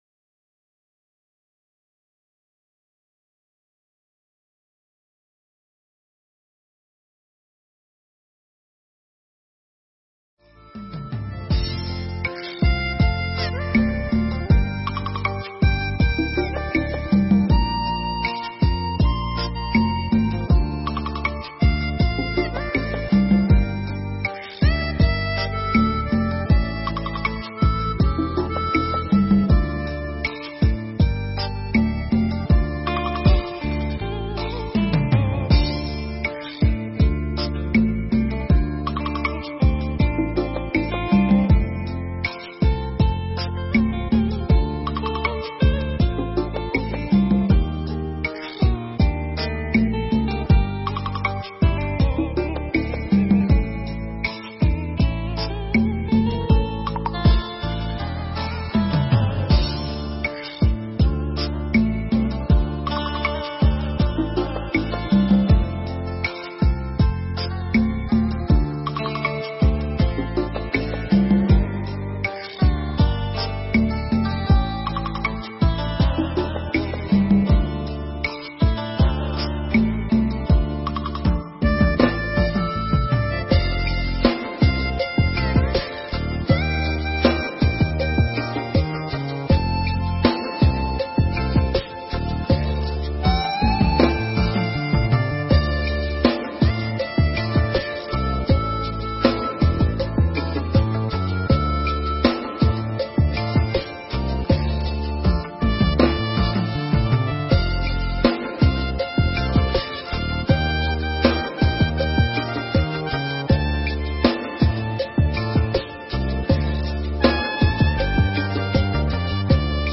Nghe Mp3 thuyết pháp Rằm Tháng Giêng Trong Phật Giáo